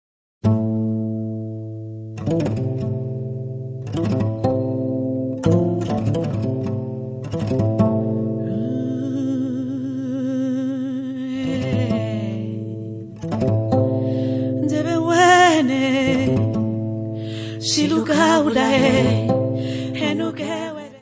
Music Category/Genre:  World and Traditional Music